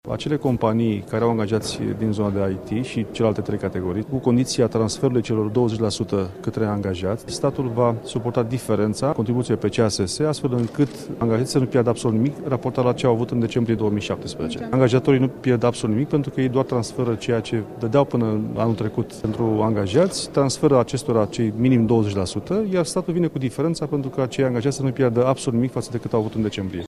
Ministrul Finanţelor, Eugen Teodorovici a explicat ce prevede noul mecanism care va fi propus pentru aprobare într-o viitoare şedinţă de guvern.